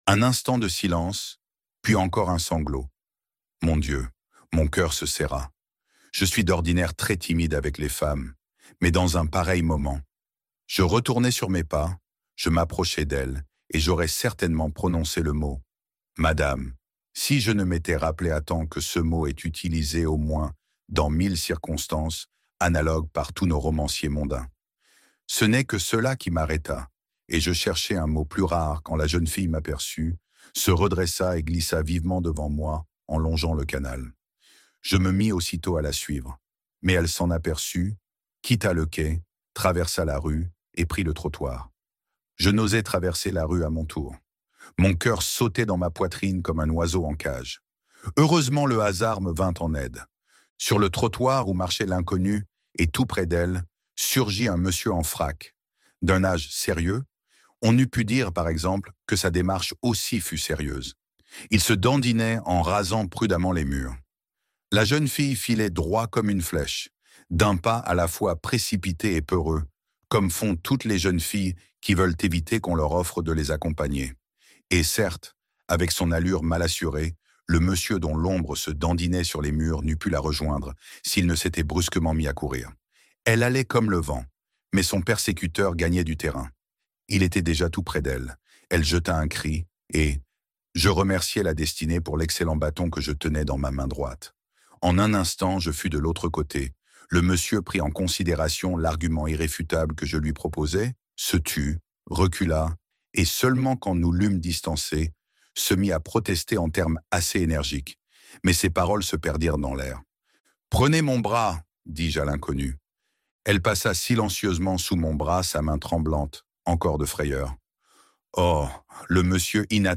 Les Nuits blanches- Livre Audio